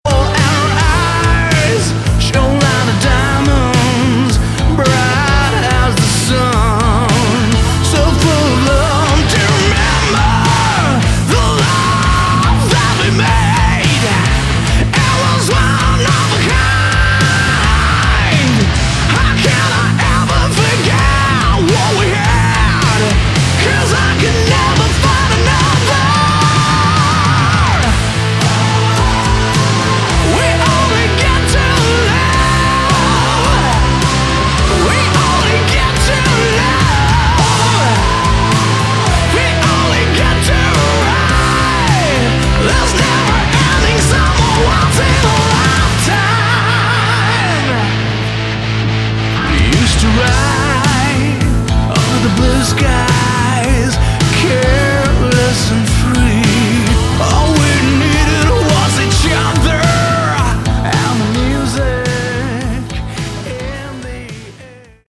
Category: Hard Rock
vocals, rhythm guitars
bass, keyboards
lead guitars
drums